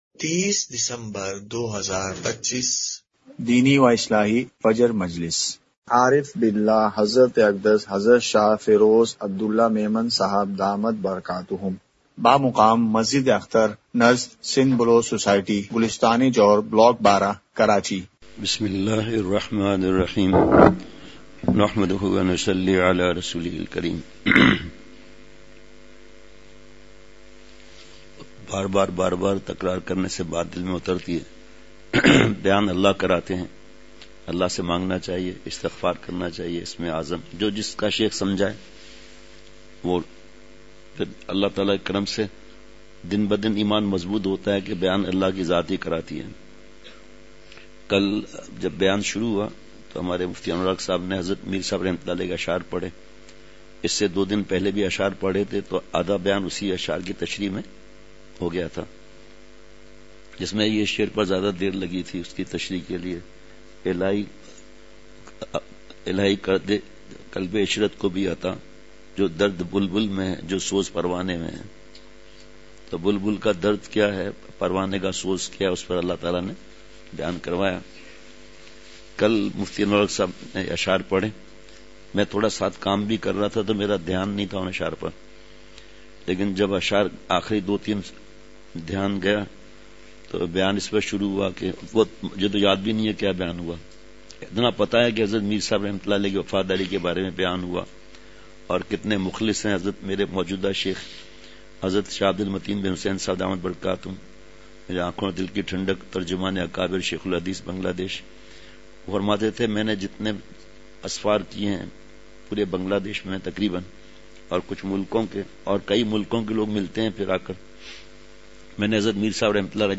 فجر مجلس۳۰ دسمبر ۲۵ء:مجلسِ ذکر !
مقام:مسجد اختر نزد سندھ بلوچ سوسائٹی گلستانِ جوہر کراچی